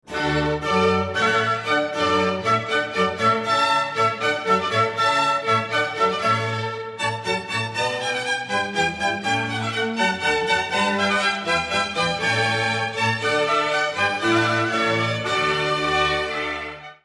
Alla hornpipe